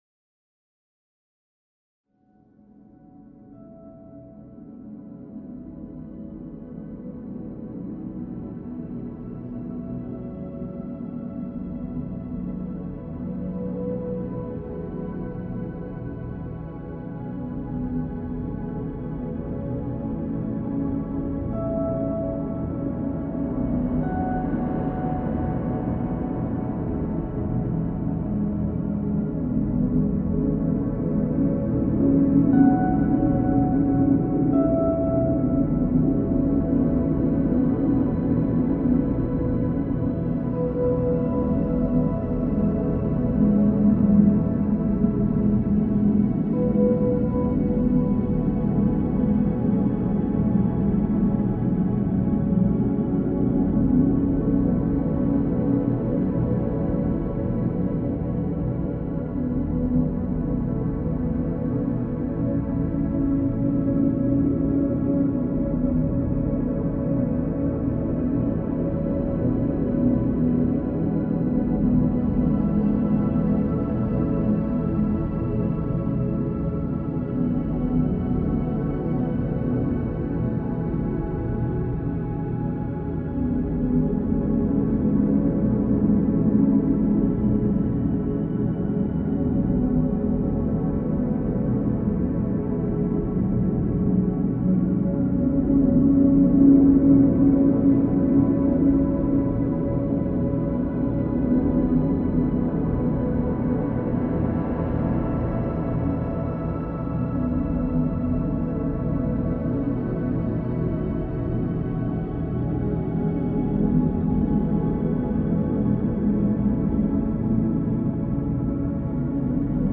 dark ambient